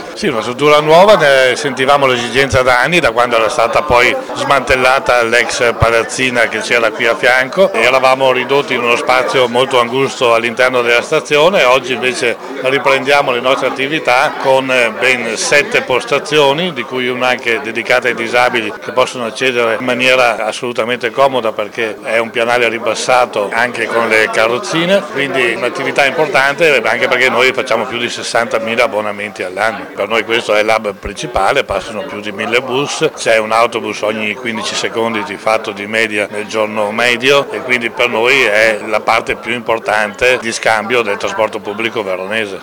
All’inaugurazione erano presenti: